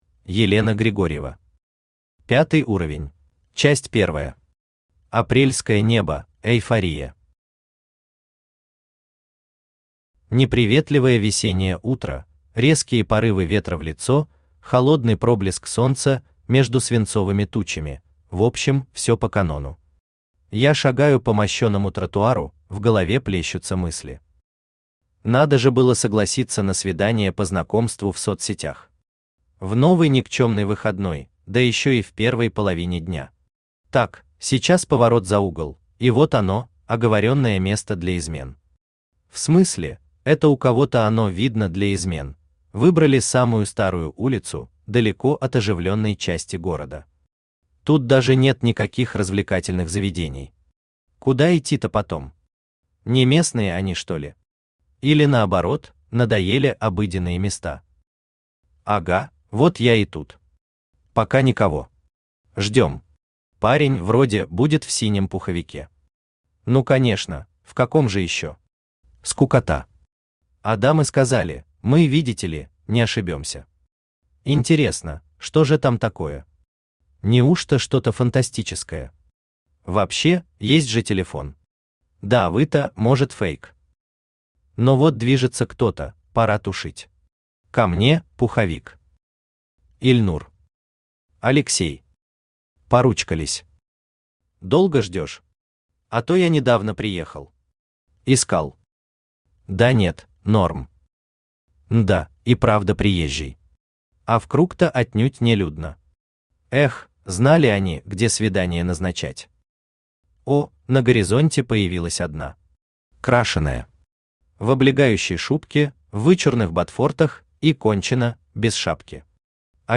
Аудиокнига Пятый уровень | Библиотека аудиокниг
Aудиокнига Пятый уровень Автор Елена Григорьева Читает аудиокнигу Авточтец ЛитРес.